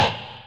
Sound effects
Arrow impact.mp3